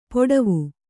♪ poḍavu